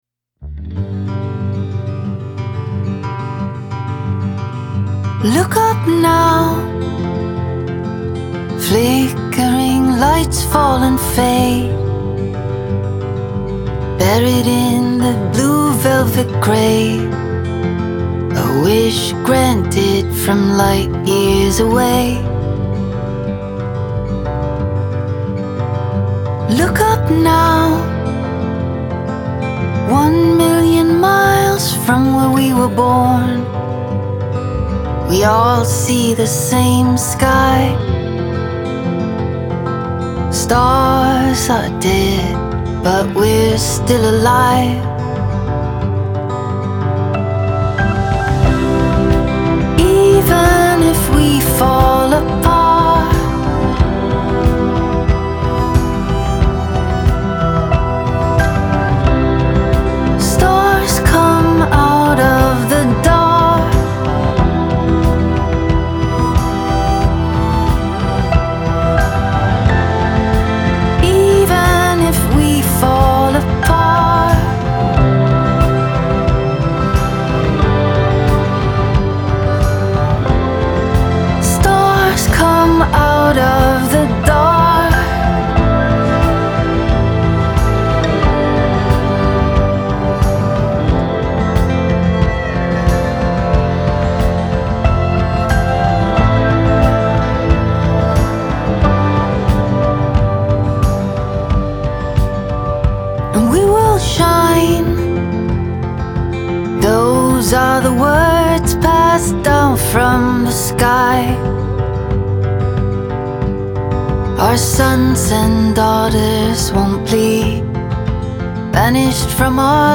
Genre : Singer & Songwriter